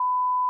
hospital_beep_alarm_02.wav